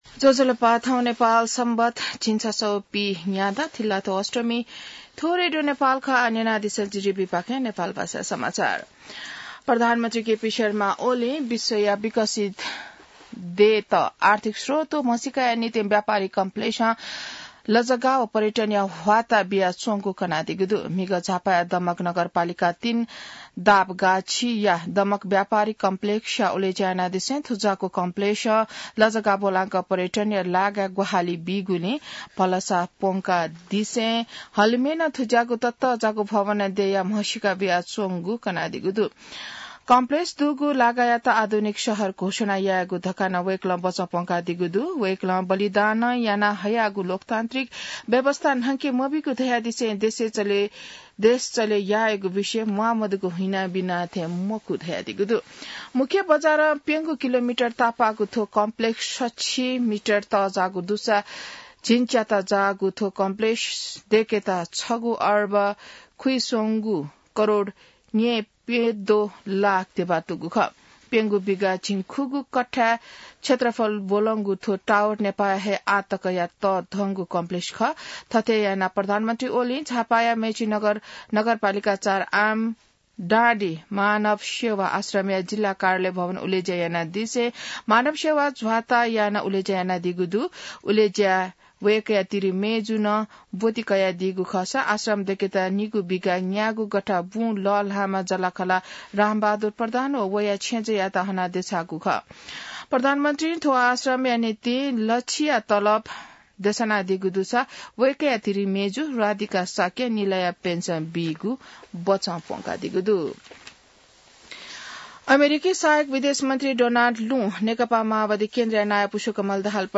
नेपाल भाषामा समाचार : २५ मंसिर , २०८१